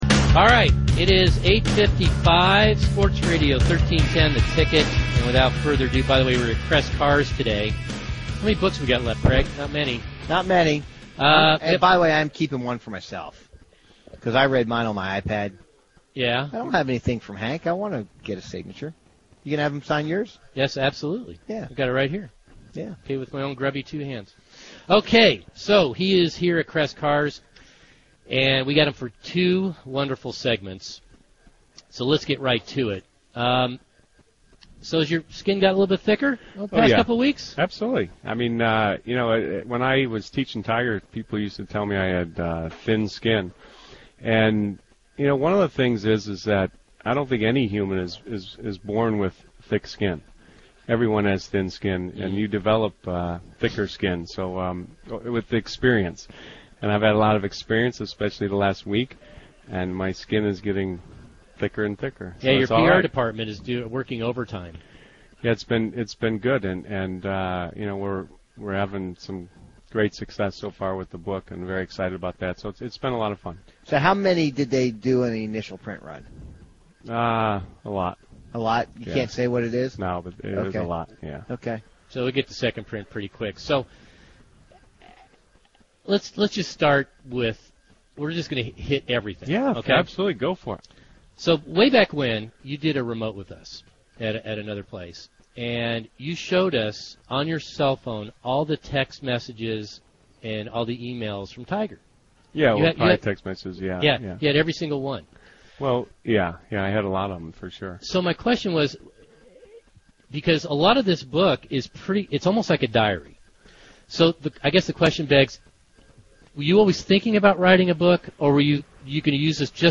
The TeeBox boys, of My Avid Golfer fame, sat down with Hank Haney (Tiger Woods’s former swing coach) for a candid interview about Hank’s new book, “The Big Miss.”